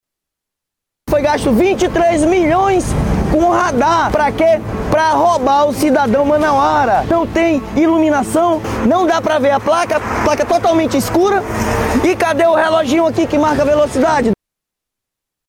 Já o vereador Sargento Salazar utilizou suas redes sociais, no último fim de semana, para levantar um alerta sobre a instalação de novos radares de fiscalização eletrônica em Manaus.